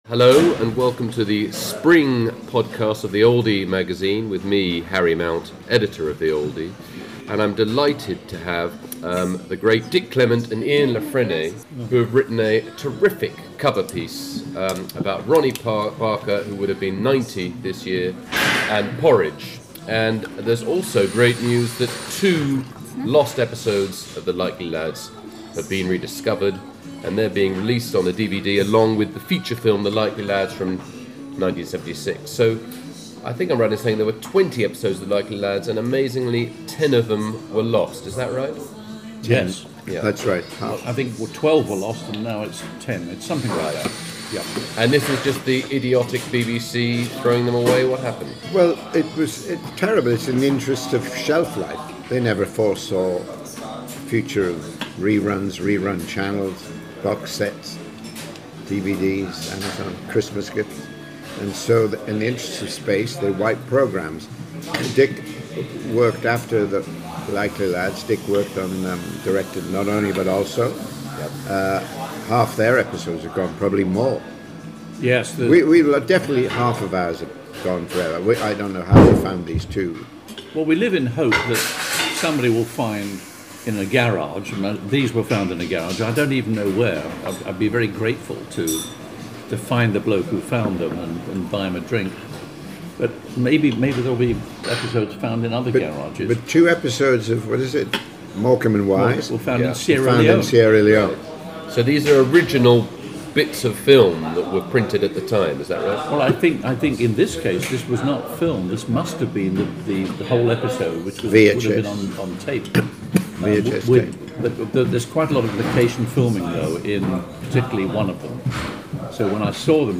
talks to that master of story telling himself, Jeffrey Archer, about his new novel, Over My Dead Body